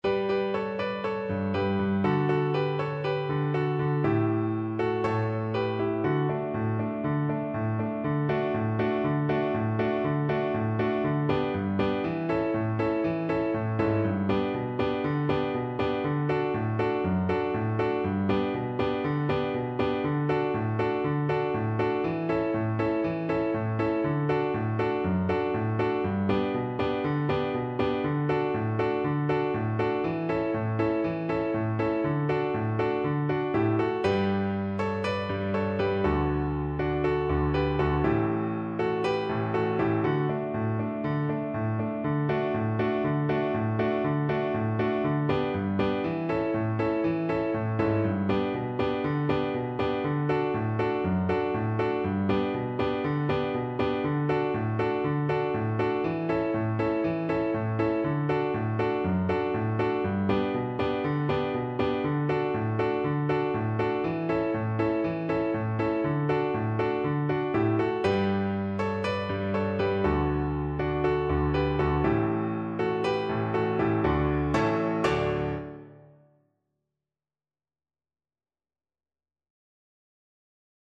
2/4 (View more 2/4 Music)
Allegro (View more music marked Allegro)
Classical (View more Classical Voice Music)